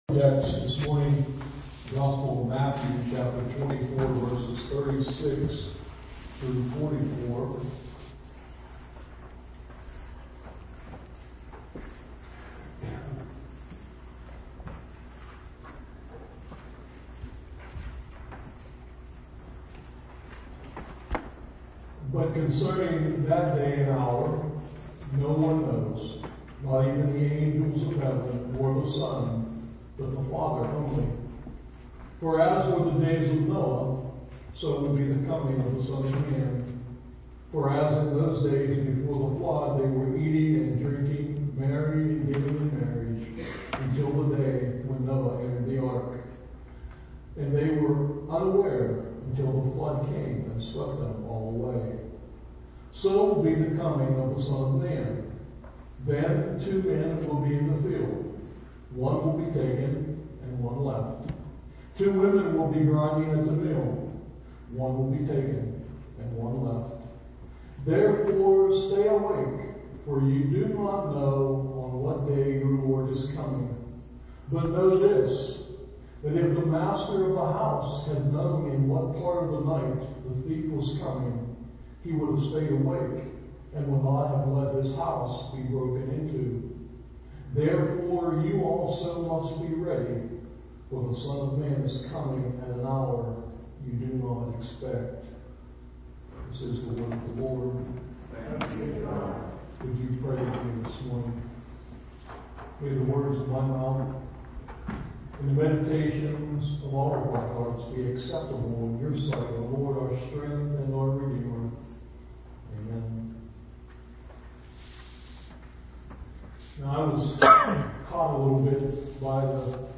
SERMON TEXT: Matthew 24:36-44